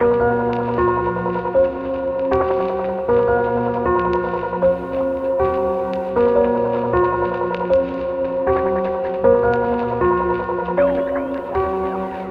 悲伤的钢琴循环
标签： 156 bpm Trap Loops Piano Loops 2.07 MB wav Key : Unknown Logic Pro
声道立体声